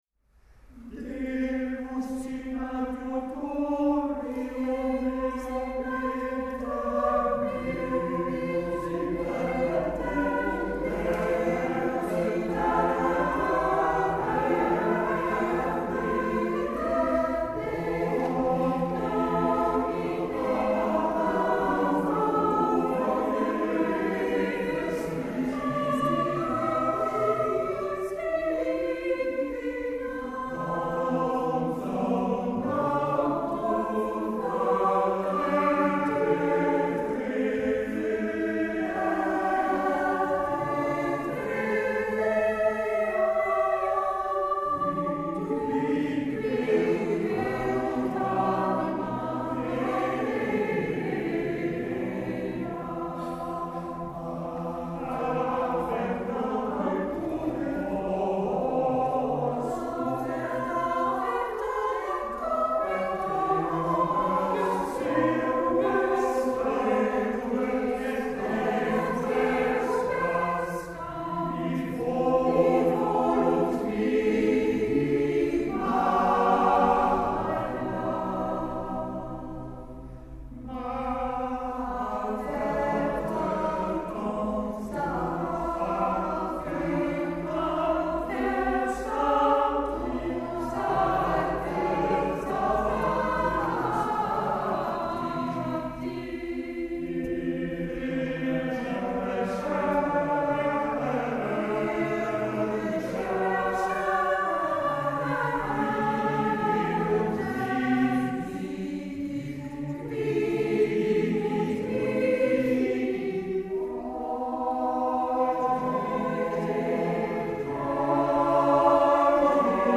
Bedankt voor jullie aanwezigheid op ons concert